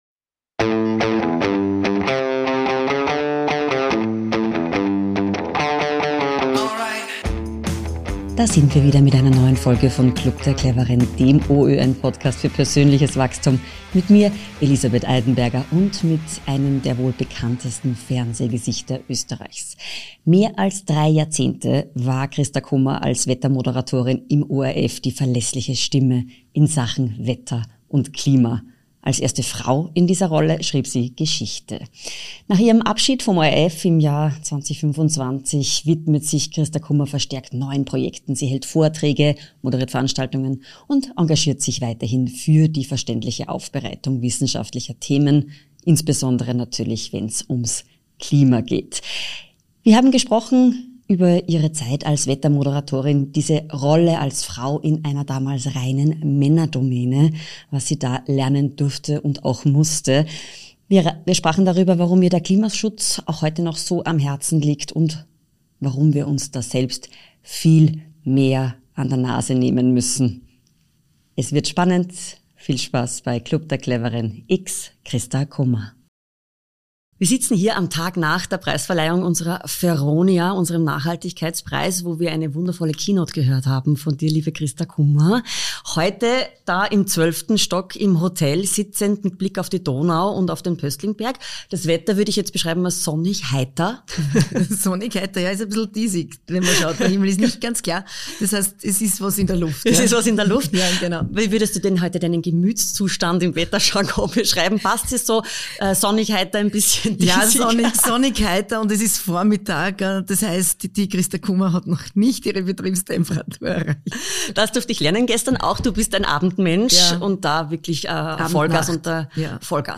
Klimatologin und Keynote-Speakerin Christa Kummer erklärt im Podcast-Interview, warum wir bei Nachhaltigkeit alle selbst die Verantwortung tragen.